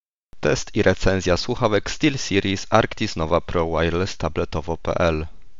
Słuchawki mają na pokładzie wbudowany, wysuwany mikrofon, który podczas rozmów niestety nie może być schowany w obudowie – MUSI być wysunięty.
steelseries-arctis-nova-pro-wireless-recenzja-test-mikorofnu.mp3